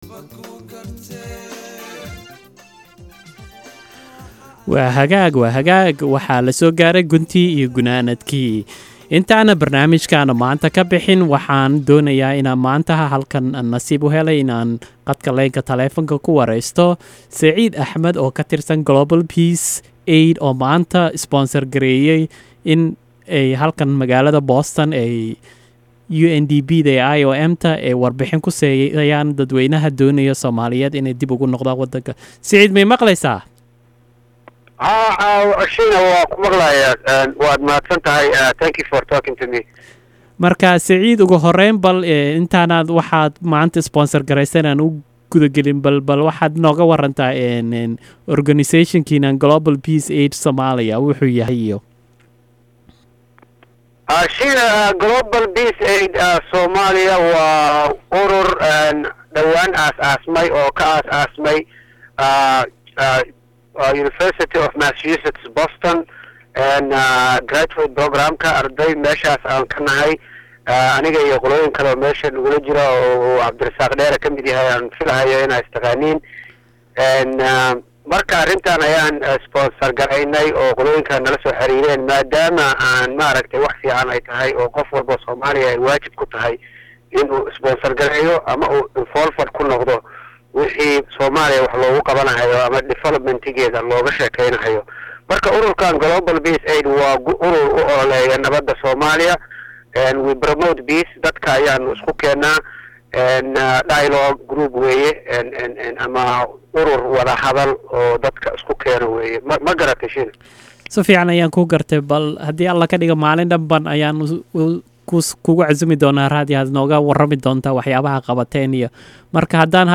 Waraysigii waa kane